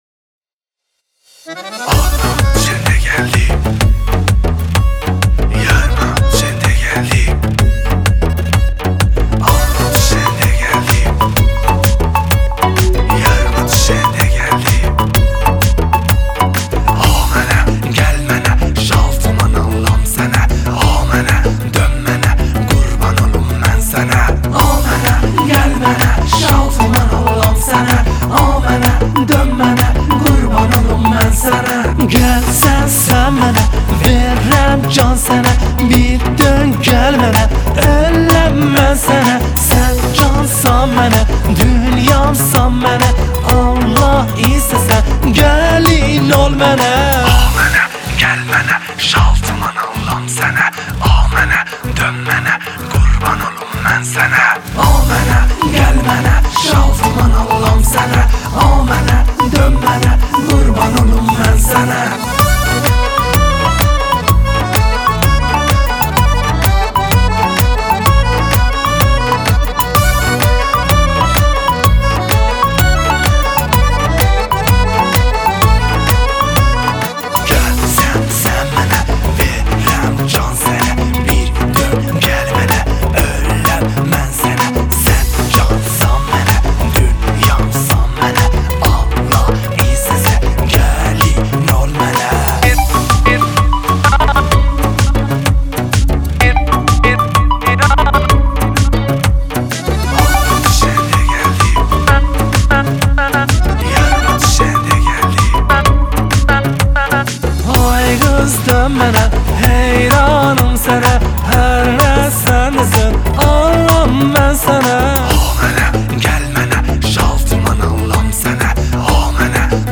خواننده پاپ آذری و ترک زبان ایرانی ست